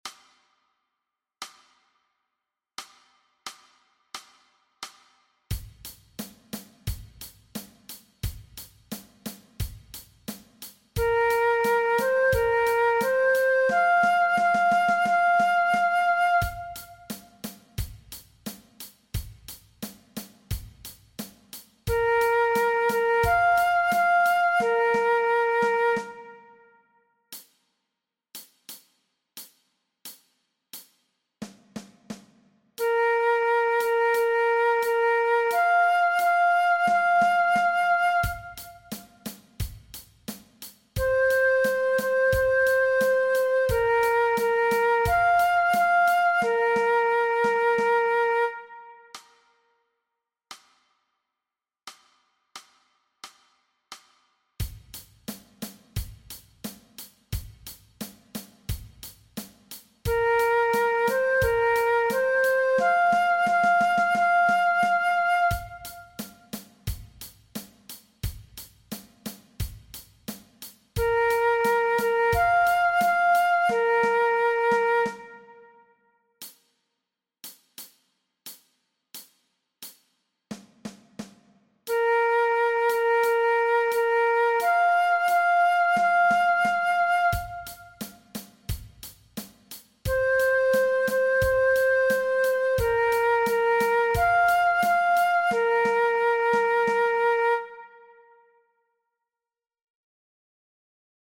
Aangepaste oefening NT 3.3 partij B Dwarsfluit
Dwarsfluit aangepast
MEESPEELTRACK-NT-3.3-B-Fluit-alternate.mp3